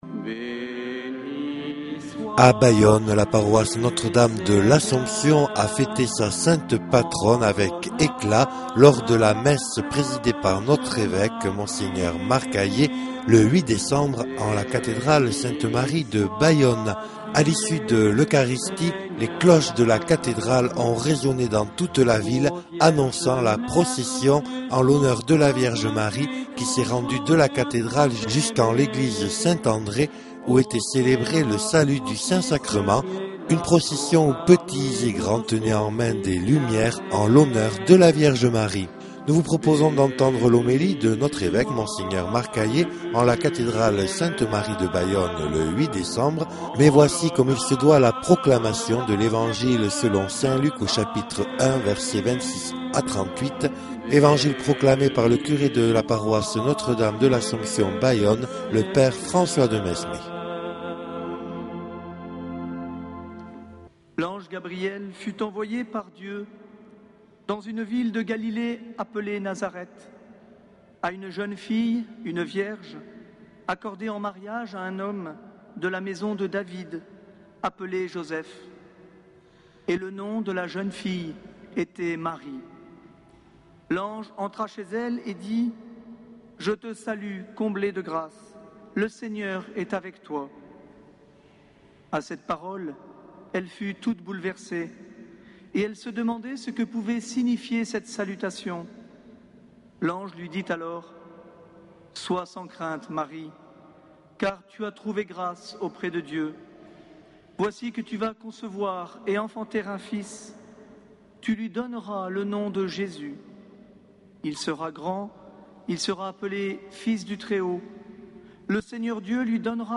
8 décembre 2009 - Cathédrale de Bayonne - Fête de l'Immaculée Conception
Les Homélies
Une émission présentée par Monseigneur Marc Aillet